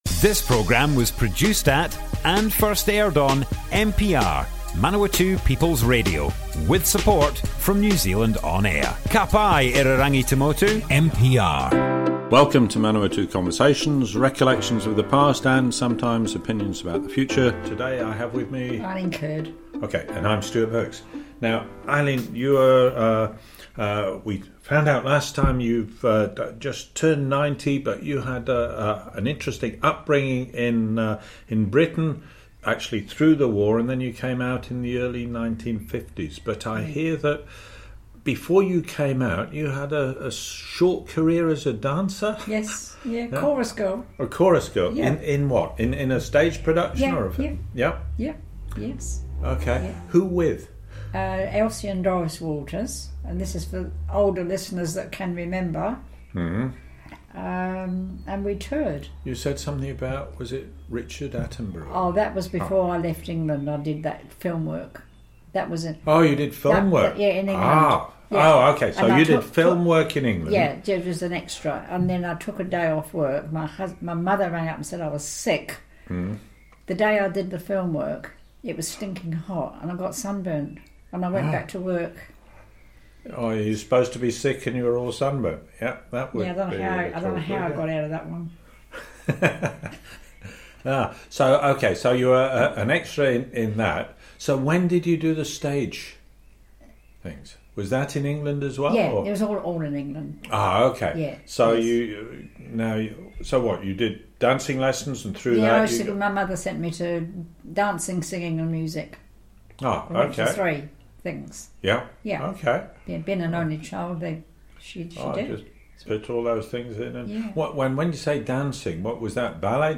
Manawatu Conversations More Info → Description Broadcast on Manawatu People's Radio, 18th January 2022.
oral history